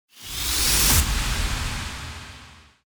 FX-1869-WIPE
FX-1869-WIPE.mp3